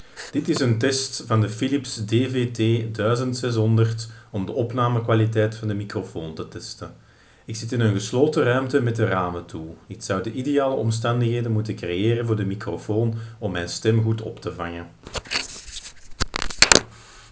First in a closed room, then in the same room but with the windows open, and then from a meter away from the microphone.
Audio fragment 1 (window closed)
The pen captures our voice well, although it is advisable to keep the recorder nearby.